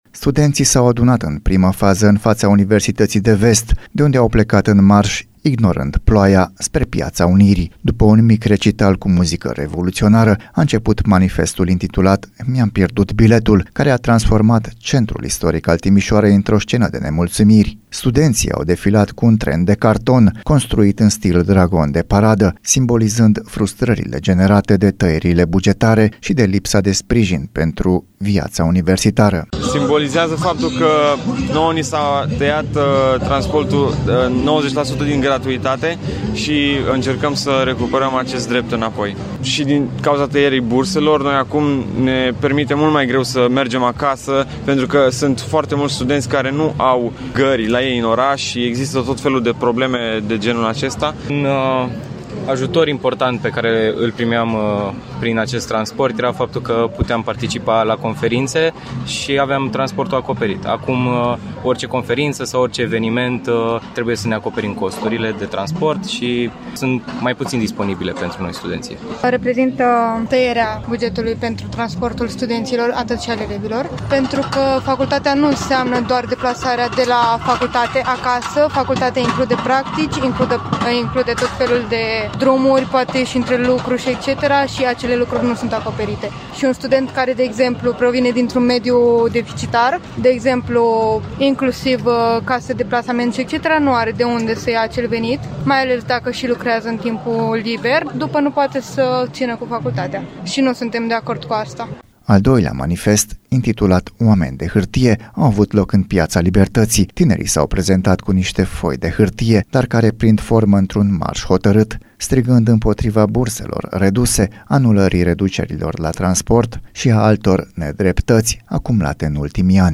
Al doilea eveniment a avut loc în Piața Libertății, unde participanții au scandat împotriva reducerii burselor și a anulării reducerilor la transport.